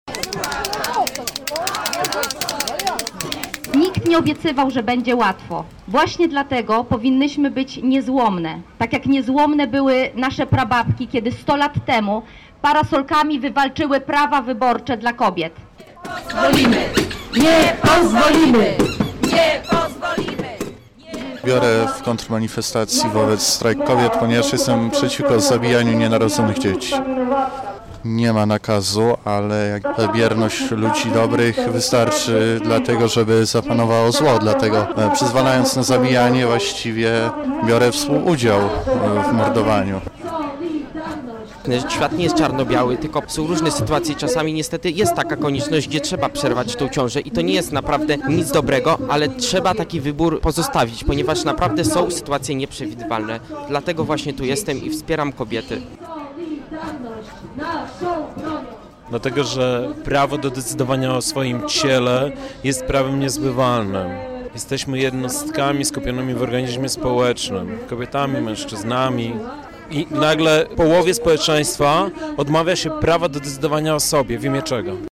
Na manifestacji był nasz reporter